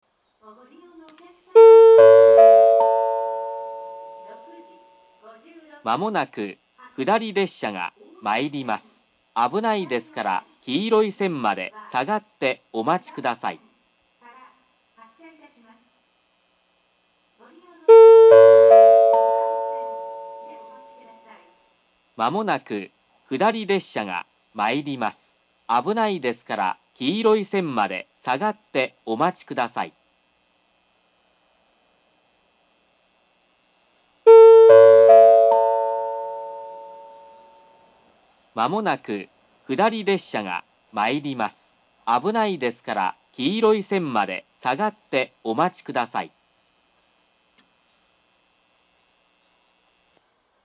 ２番線下り接近放送 １番線下りと同じです。